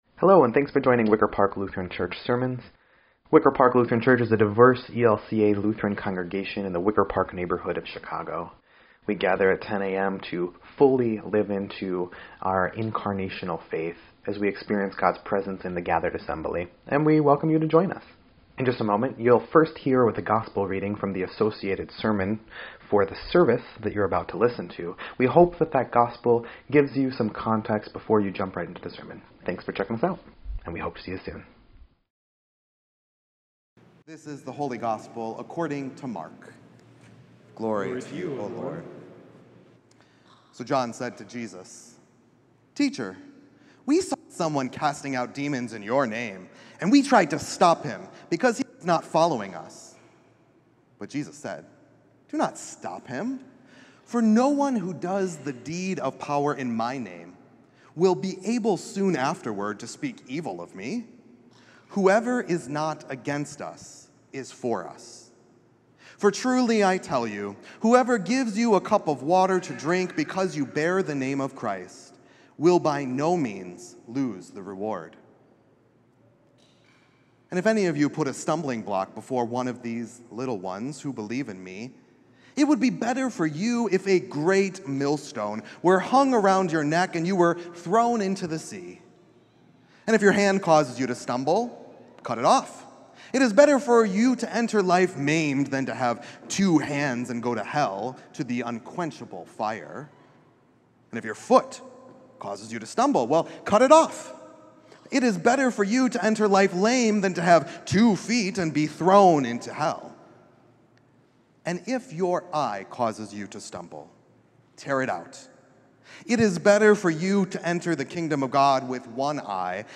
9.29.24-Sermon_EDIT.mp3